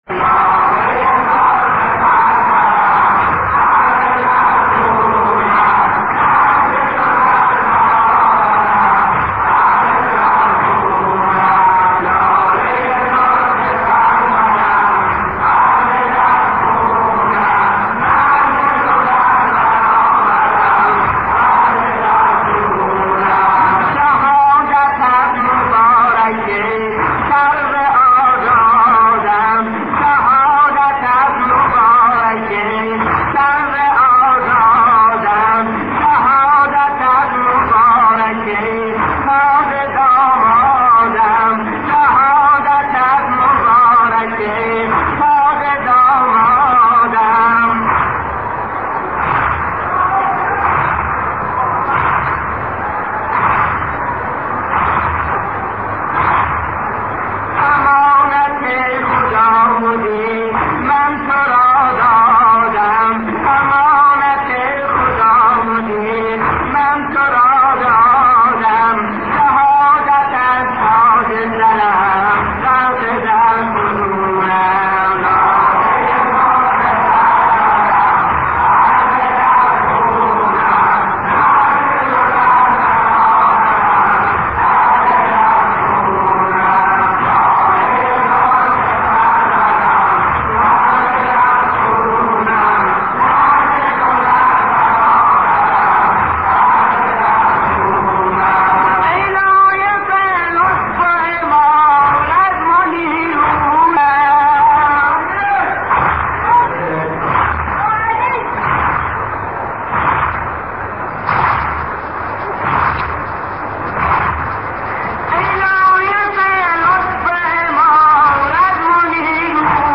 صوت سرود